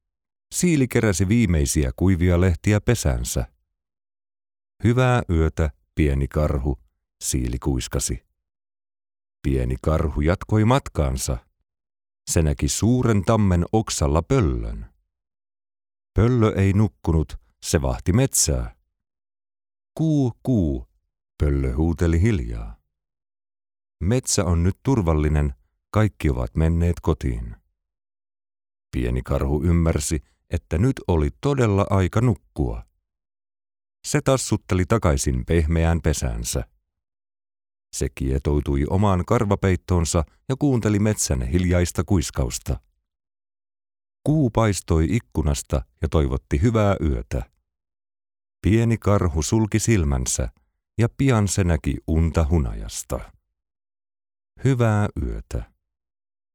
Narrator for Audio Books | Foreign Voice Talent
0224Audiobook-Storyteller-Narration.mp3